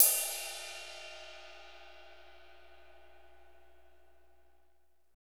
Index of /90_sSampleCDs/Northstar - Drumscapes Roland/DRM_Hip-Hop_Rap/CYM_H_H Cymbalsx